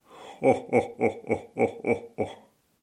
Звуки Санта-Клауса
6. Спокойный вариант Хо-Хо-ХО